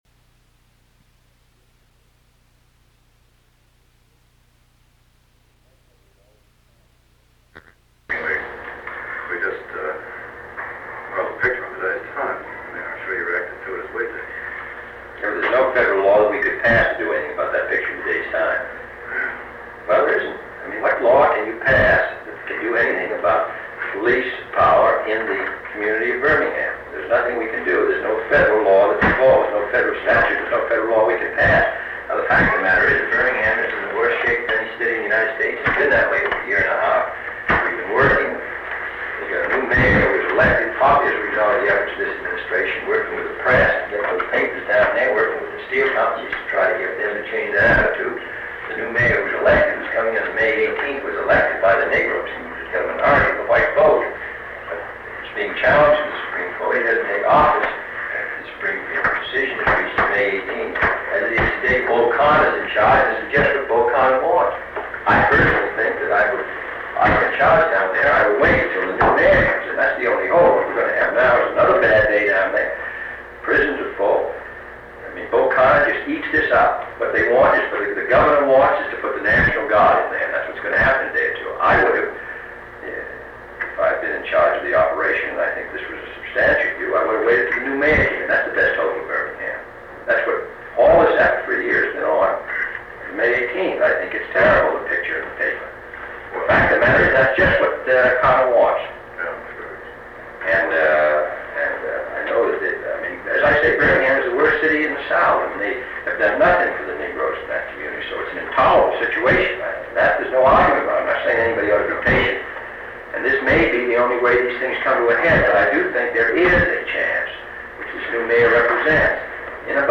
Sound recording of a meeting held on May 4, 1963, between President John F. Kennedy and his guests, twenty members of the organization Americans for Democratic Action (ADA) present for a lobbying session. The President takes the opportunity to pitch the successes of his administration’s legislative agenda to this liberal action group that on occasion had been critical of some of the moves of his administration.